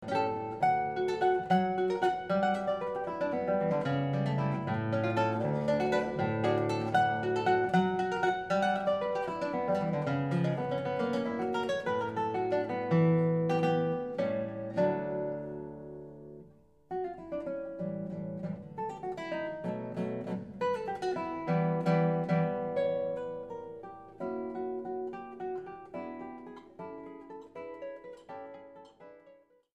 New Zealand classical guitarist composer